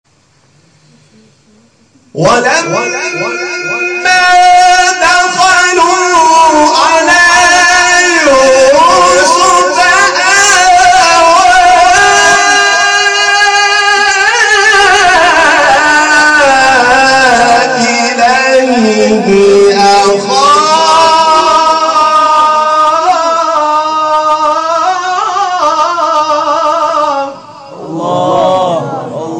شبکه اجتماعی: مقاطع صوتی از قاریان ممتاز کشور را می‌شنوید.
مقطعی از حمید شاکرنژاد